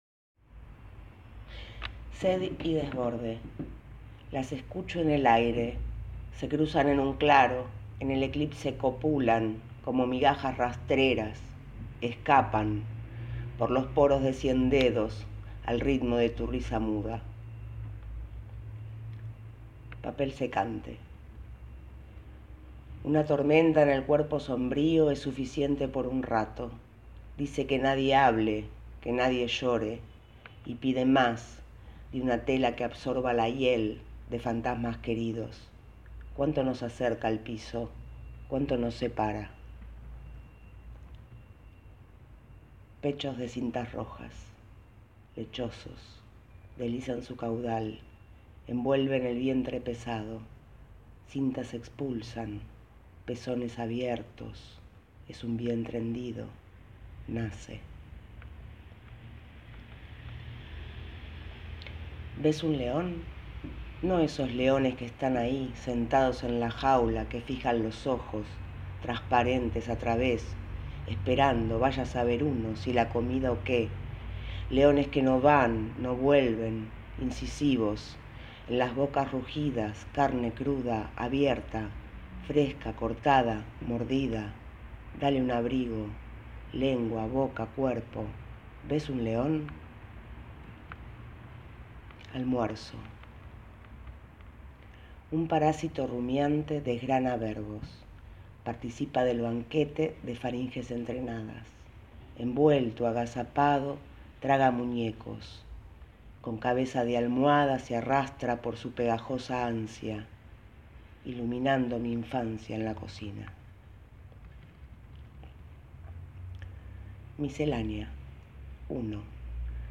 Nueva selección de poemas